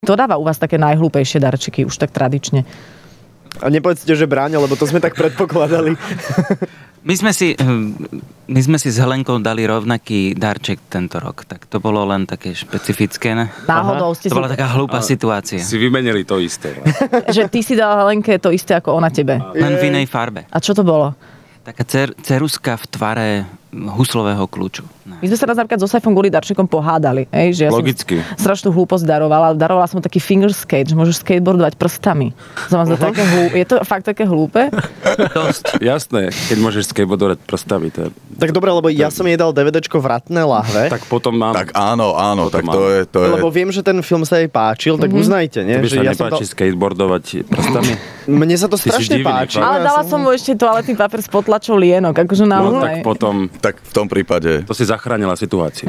Dnes ráno bola hosťami Rannej šou skupina Fragile.
Hádka pre darčeky